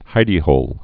(hīdē-hōl)